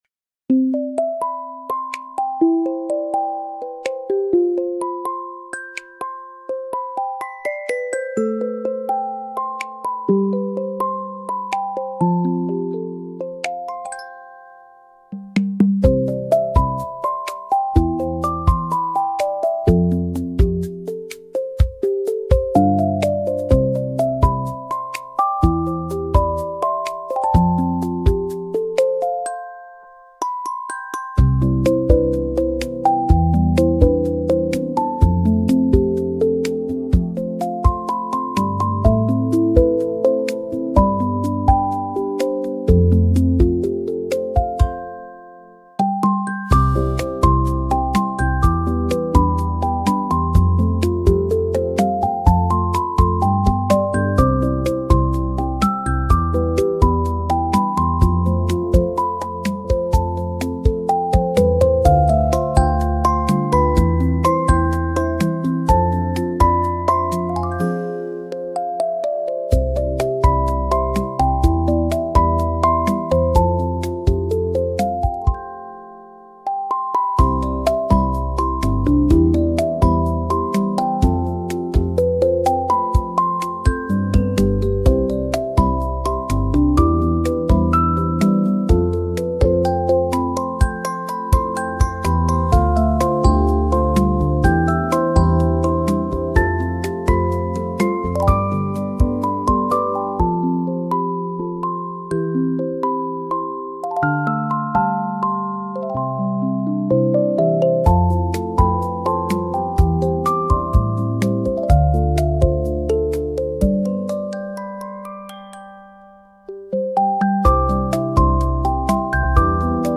ゆめかわいいフリーBGM🧸🎧🫧
とろける音色～リラックスタイムに